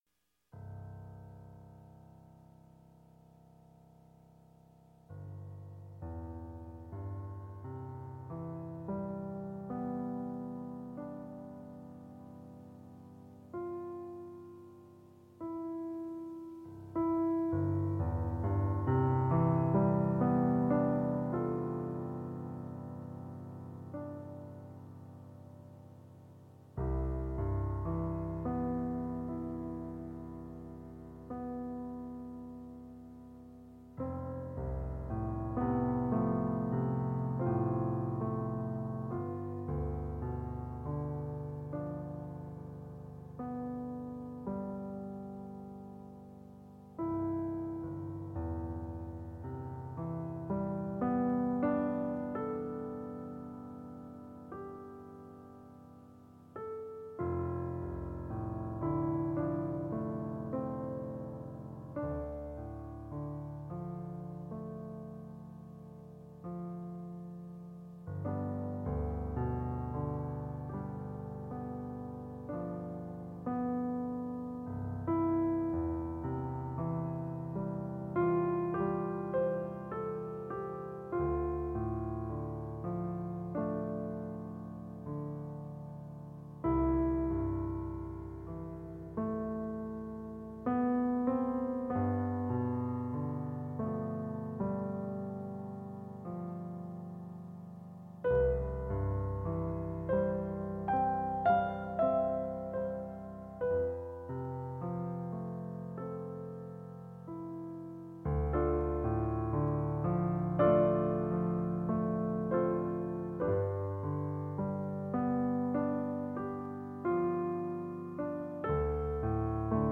2 piano pieces at 25 minutes each.